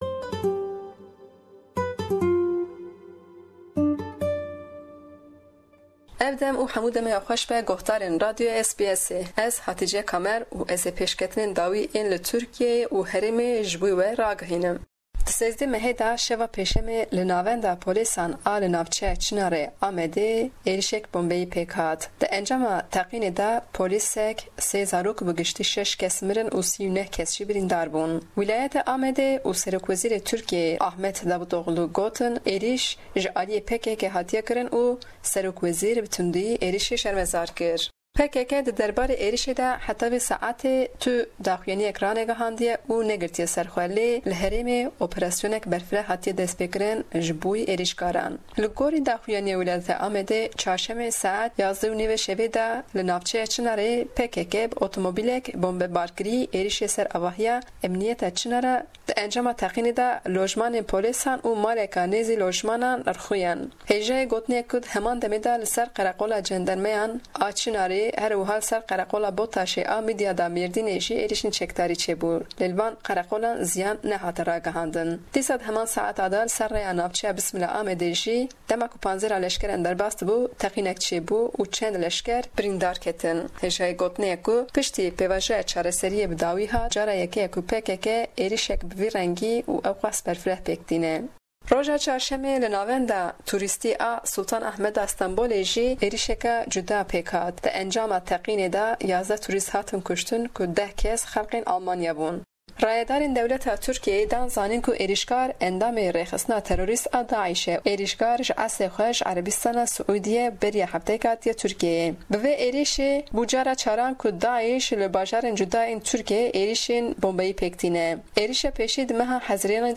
Herweha rewşa navçeyên qedexe, guhertina yasaya veşartina miriyan û bandora wê li ser navçeyên qedexe. Ji silopê dengê du kesan li ser rewşê û babetên din di raportê de hene. ê bi navê nobeta sipî ya aşitiyê mijarên di nav raportê de ne.